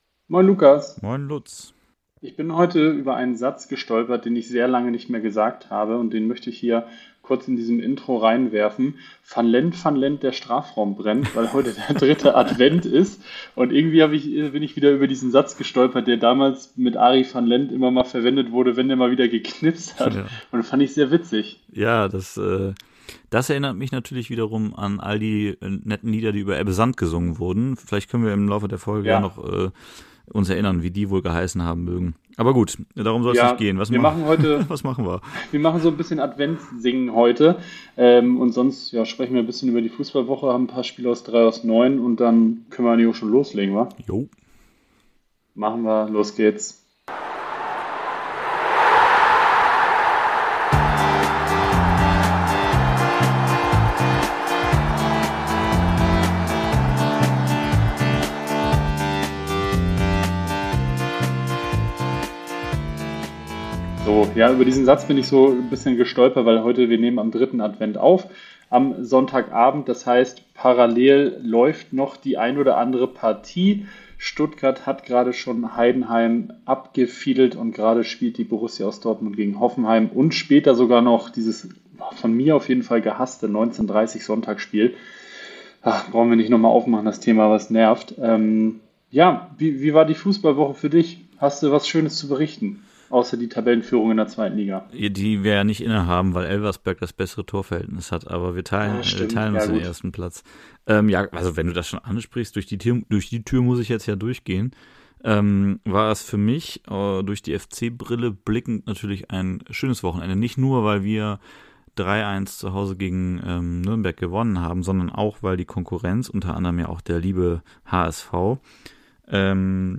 Diese Woche ein wenig rumpelig. Wir können es nicht mal auf die Technik schieben, ehrlich gesagt.